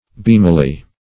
beamily - definition of beamily - synonyms, pronunciation, spelling from Free Dictionary Search Result for " beamily" : The Collaborative International Dictionary of English v.0.48: Beamily \Beam"i*ly\, adv. In a beaming manner.